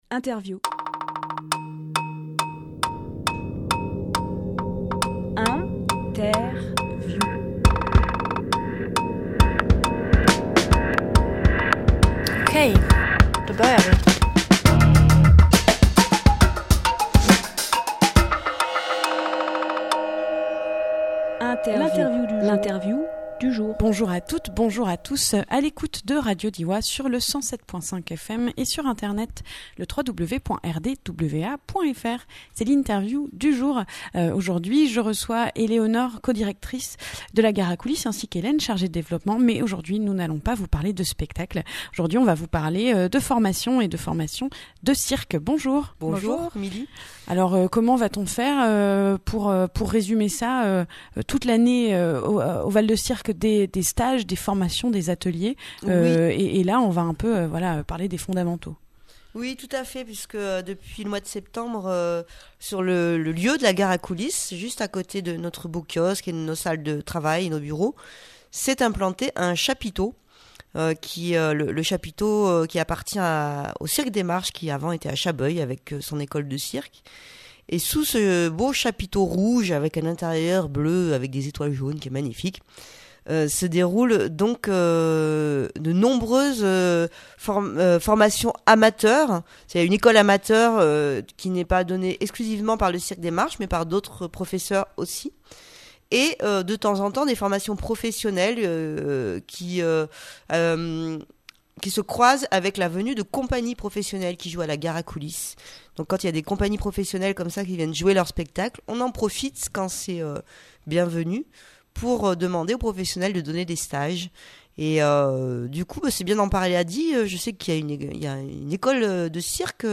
Emission - Interview
Lieu : Studio RDWA